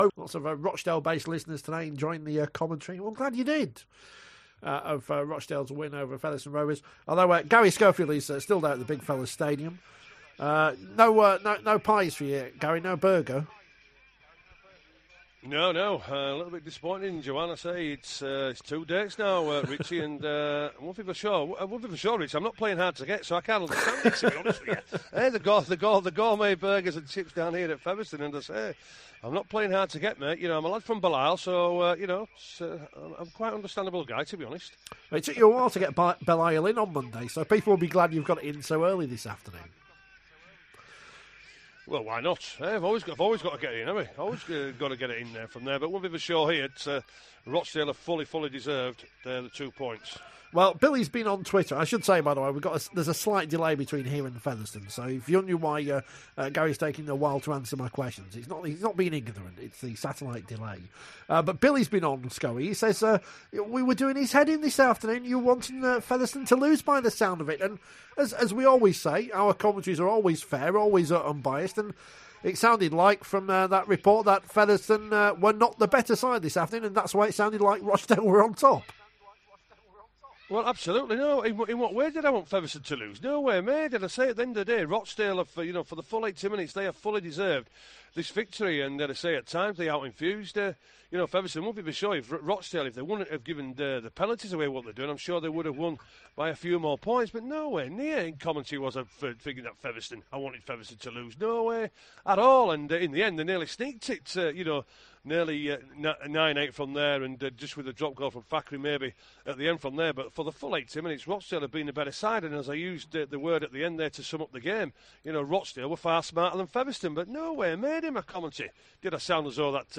A packed 30+ minutes of Rugby League discussion on another busy weekend, with a packed panel of Garry Schofield